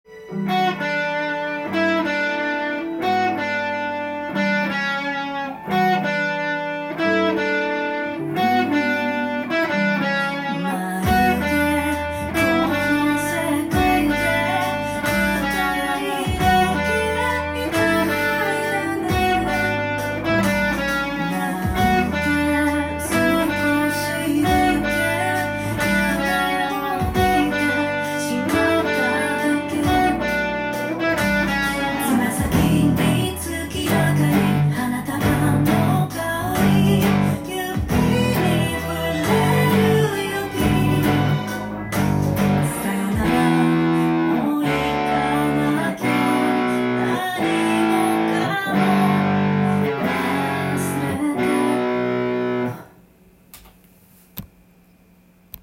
ギターTAB譜
音源に合わせて譜面通り弾いてみました
３拍子で構成されている
けだるい感じで始まる曲ですが、
譜面にしていますが、主にピアノパートをギターで弾く感じなっています。
途中でパワーコード切り替わりますが